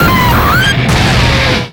Cri de Heatran dans Pokémon X et Y.